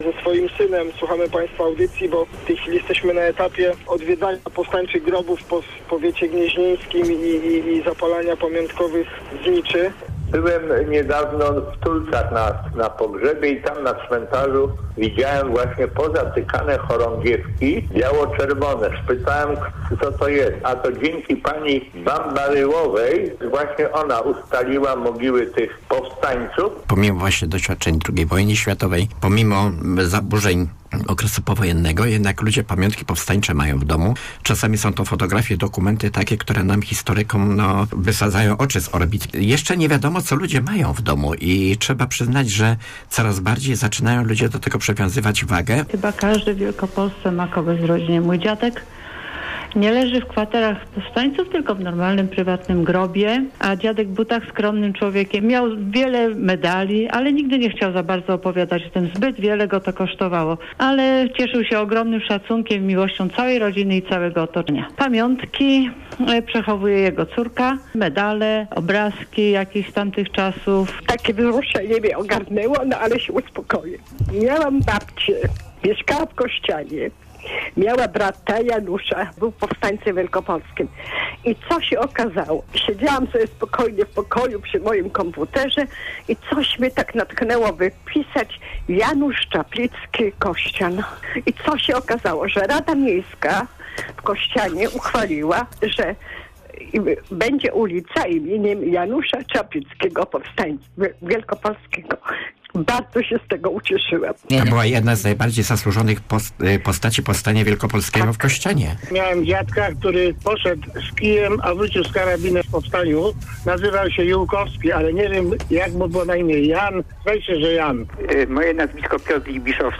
4bhm7zfsr8n7c4l_skrot-dyskusji-antenowej.mp3